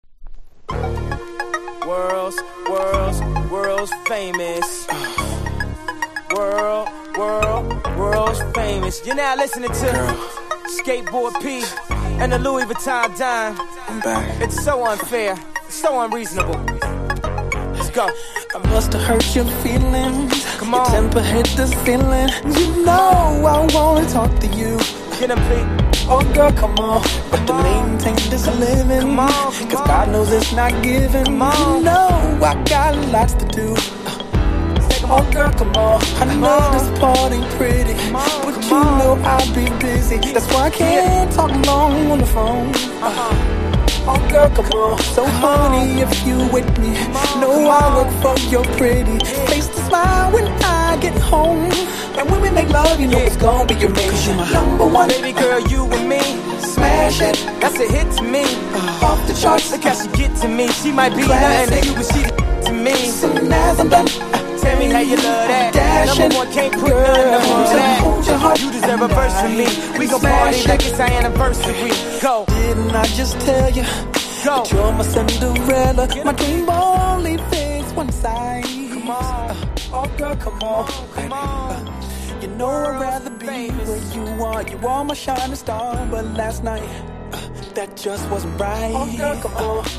# 00’S HIPHOP